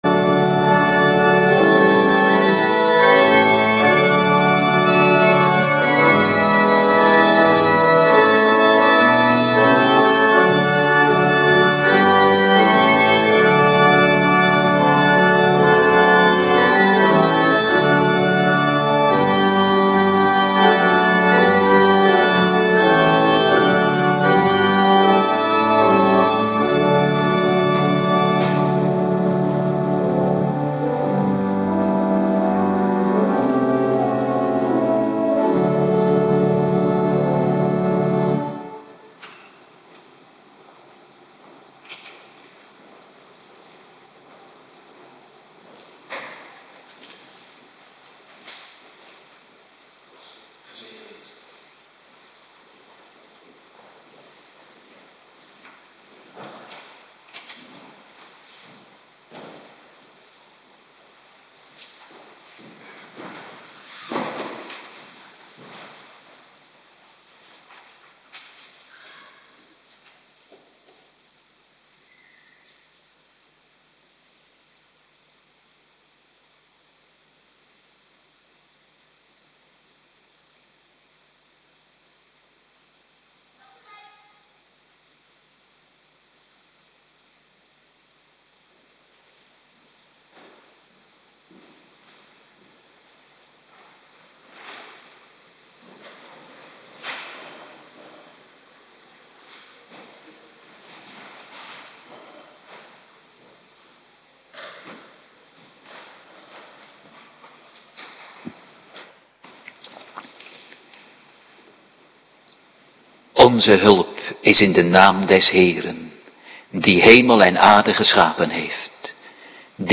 Woensdagavonddienst
Locatie: Hervormde Gemeente Waarder